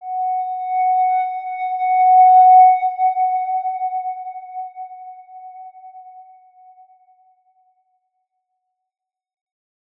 X_Windwistle-F#4-mf.wav